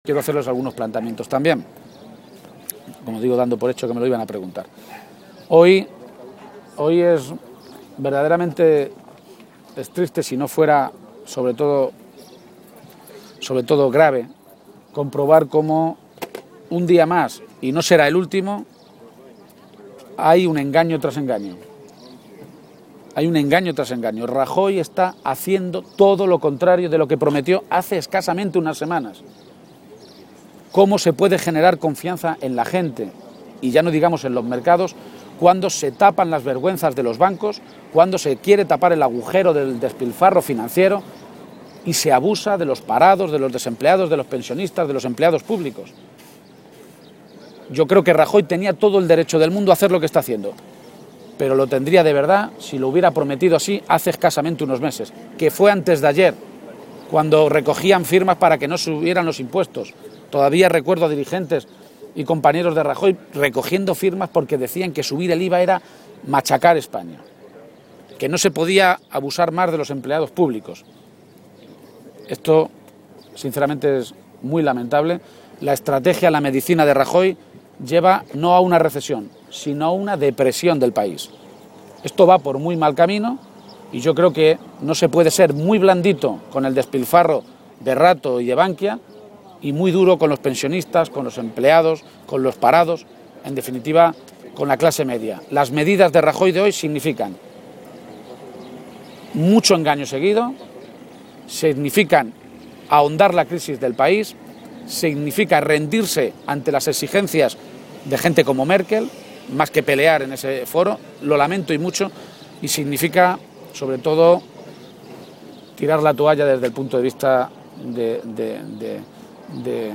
García-Page se pronunciaba de esta manera a preguntas de los medios de comunicación, y señalaba que todos los anuncios tienen un patrón común: “El de ir engaño tras engaño, haciendo exactamente lo contrario no ya de lo que prometió, sino de lo que decía hace apenas unas semanas. Todavía recuerdo a muchos dirigentes del PP haciendo campaña contra la subida del IVA o diciendo que a los empleados públicos ya se les habían pedido muchos sacrificios”.
Cortes de audio de la rueda de prensa